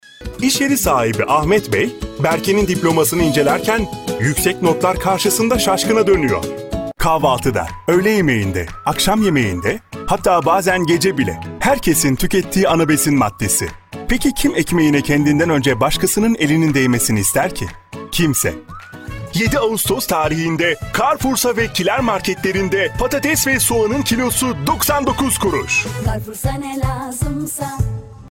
配音风格： 温情 讲述 自然